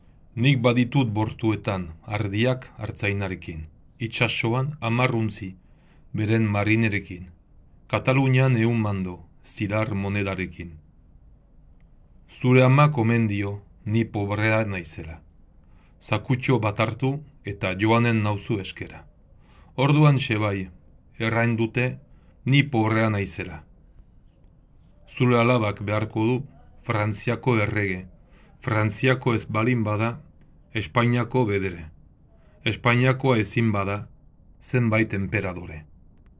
Nik-baditut-bortuetan-pronounciation.wav